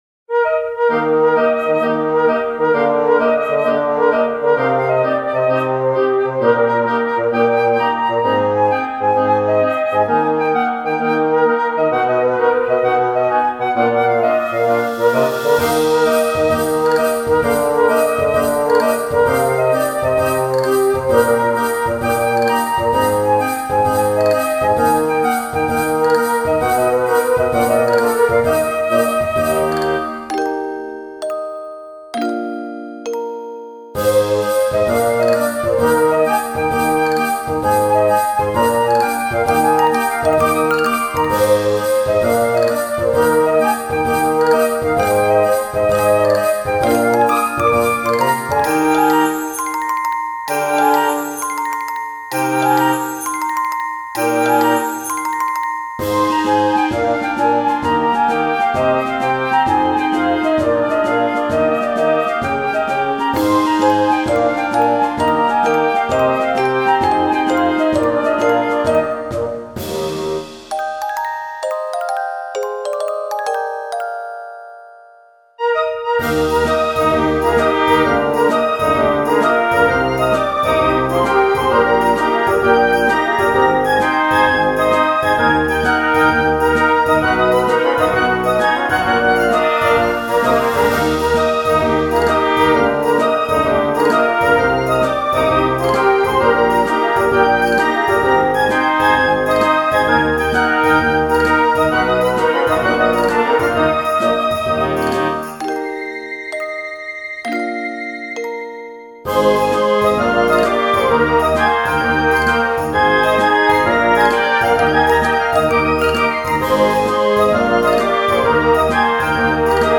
ogg(L) メルヘン ファンシー 不思議